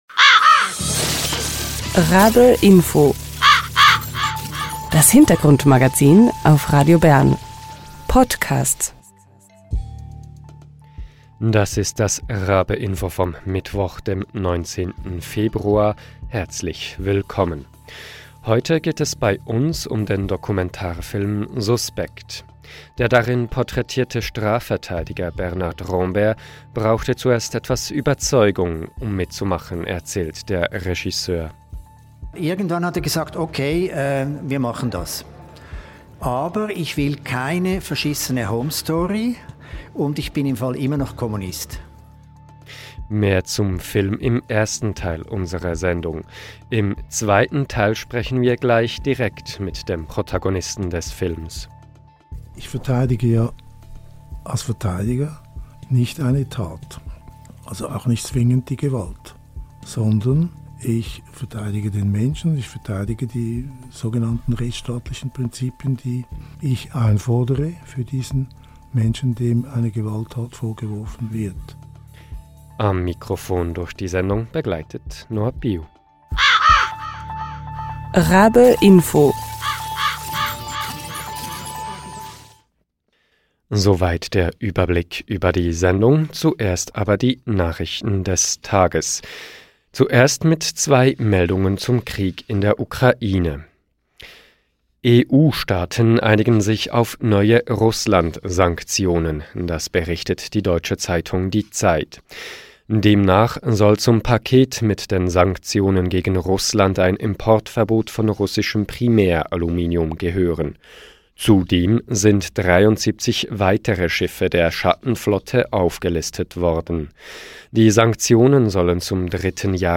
Im ersten Teil der Sendung stellen wir den Film genauer vor. Im zweiten Teil sprechen wir mit dem Protagonisten des Films.